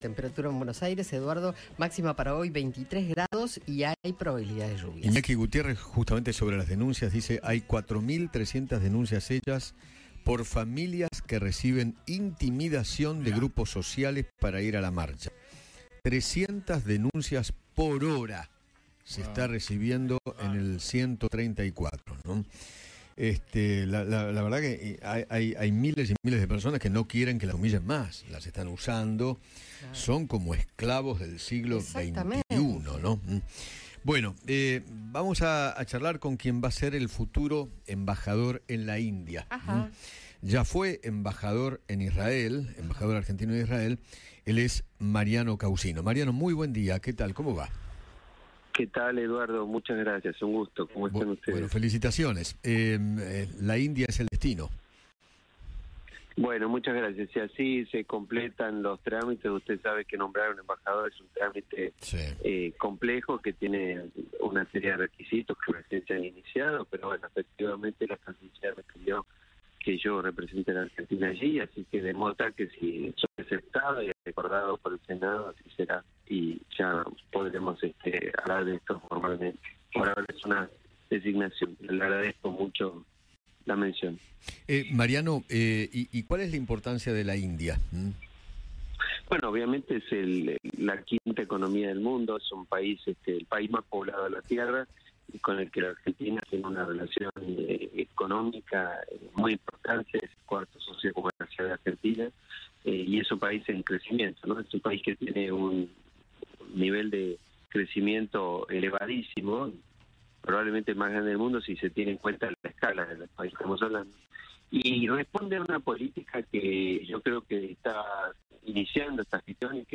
Mariano Caucino, futuro embajador en la India, dialogó con Eduardo Feinmann sobre la relación bilateral de Argentina con ese país.